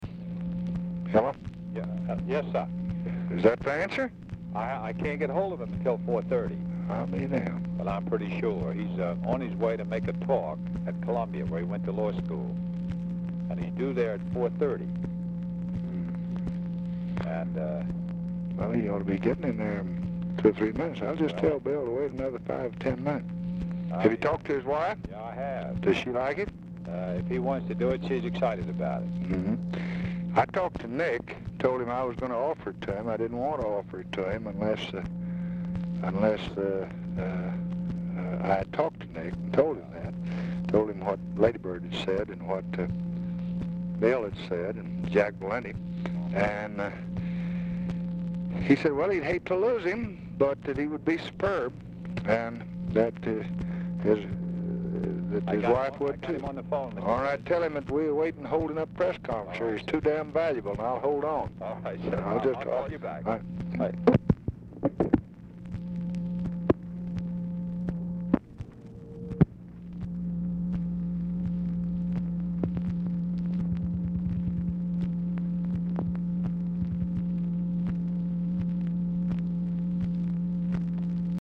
Telephone conversation # 9901, sound recording, LBJ and STUART SYMINGTON, 3/18/1966, 4:20PM | Discover LBJ
Format Dictation belt
Location Of Speaker 1 Oval Office or unknown location